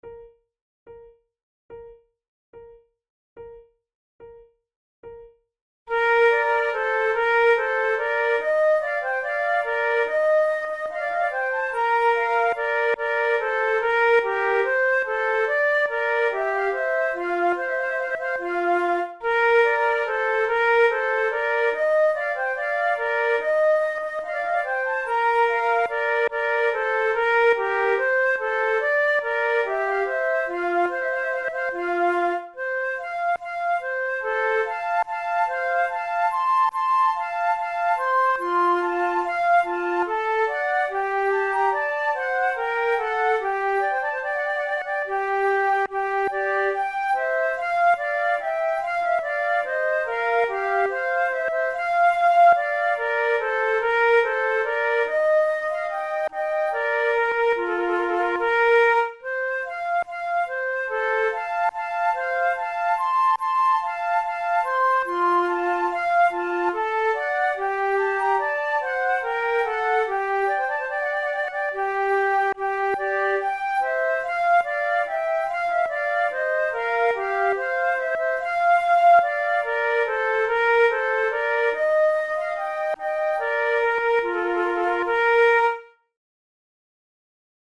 a sonata for 3 flutes in B-flat major